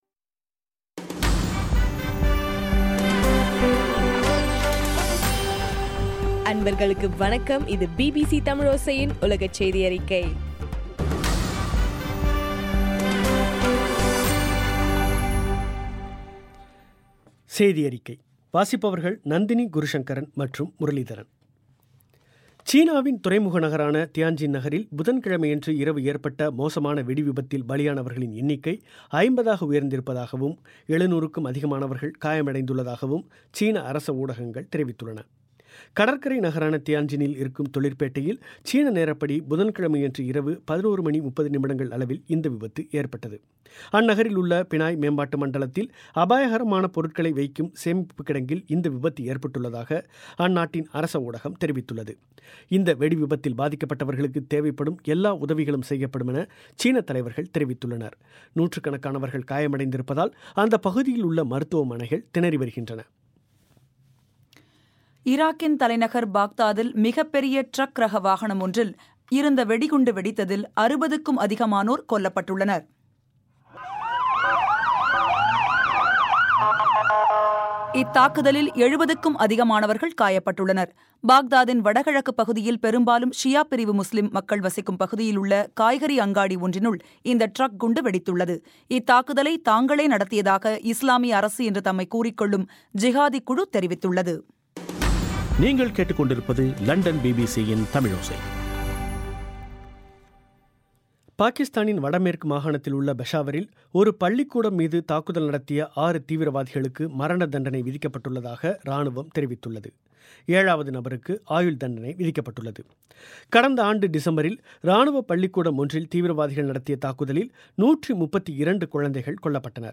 ஆகஸ்ட் 13 பிபிசியின் உலகச் செய்திகள்